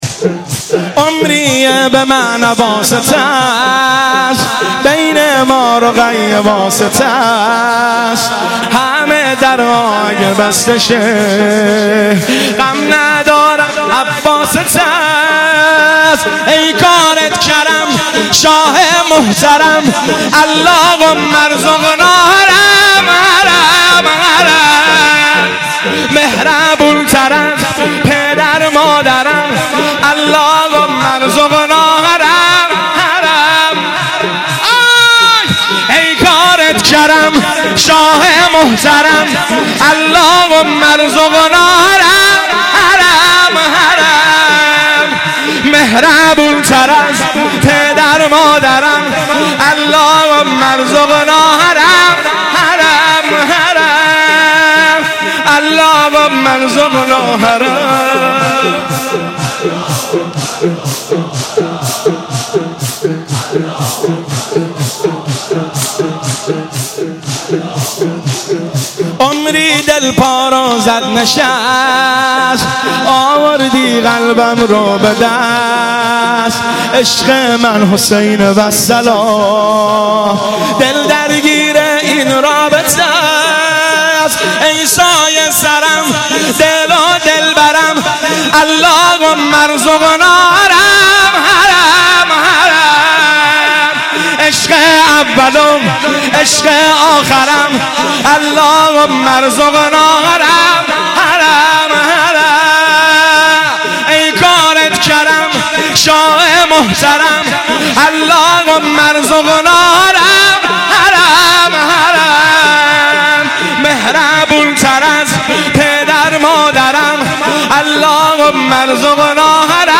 مداحی عمریه به من حواست است(شور)
شب هفتم محرم 1396